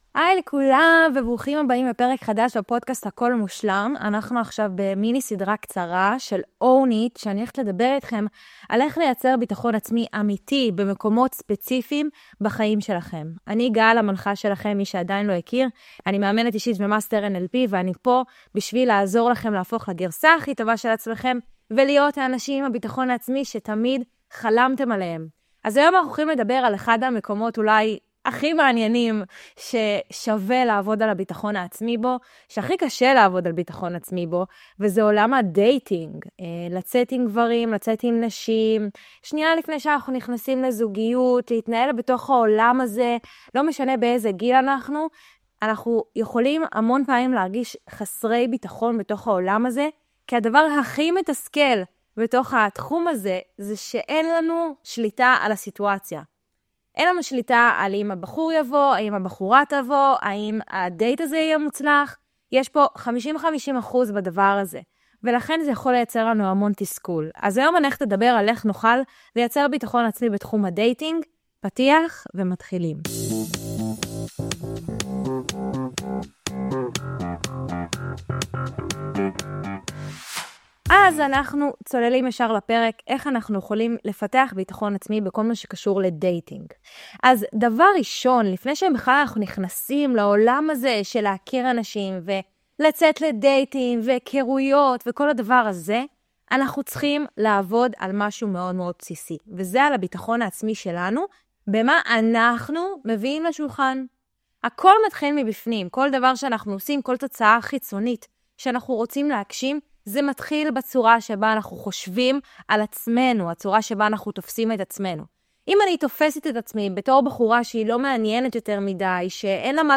מיני עונה של פרקי סולו קצרים וממוקדים שיעזרו לכם לפתח ביטחון עצמי במקומות ספציפיים בחייכם!